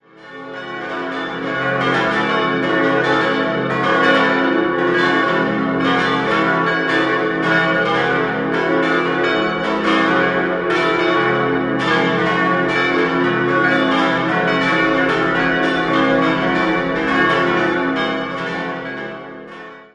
6-stimmiges Geläut: cis'-e'-fis'-gis'-a'-cis''